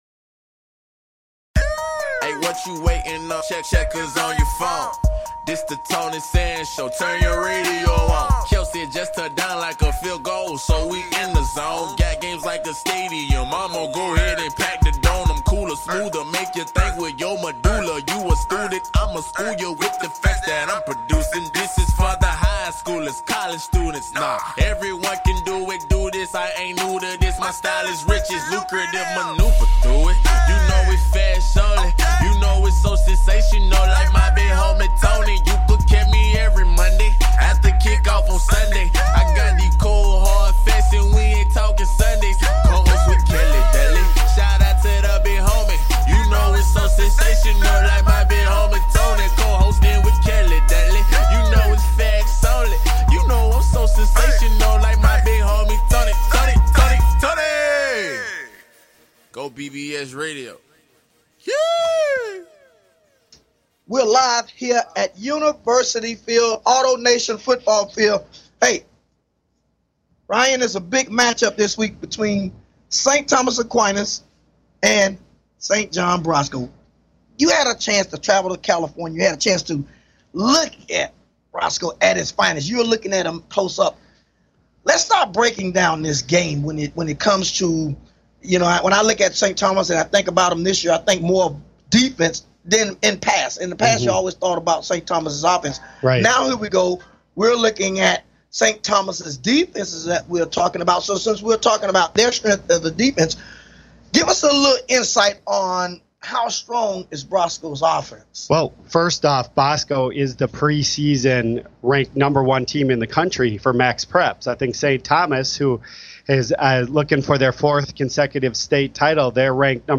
Talk Show Episode
Live at Univ. Field